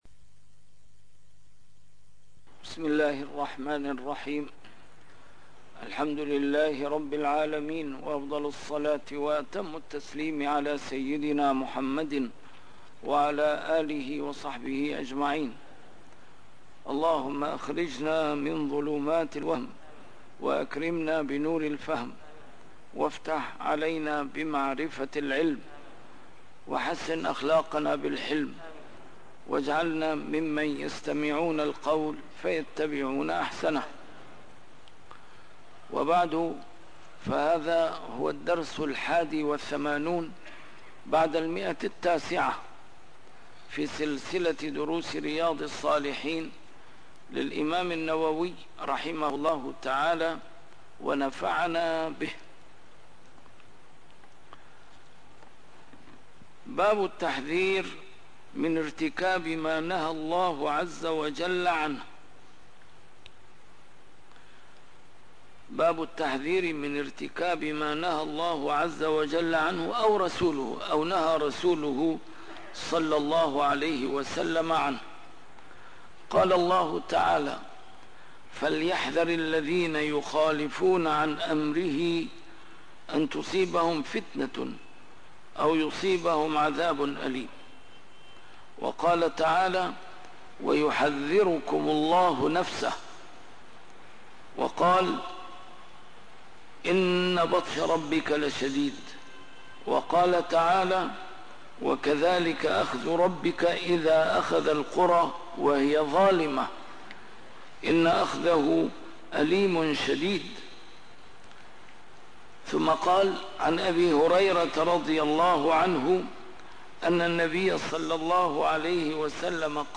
A MARTYR SCHOLAR: IMAM MUHAMMAD SAEED RAMADAN AL-BOUTI - الدروس العلمية - شرح كتاب رياض الصالحين - 981- شرح رياض الصالحين: التحذير من ارتكاب ما نهى الله ورسوله عنه - ما يقوله ويفعله من ارتكب منهياً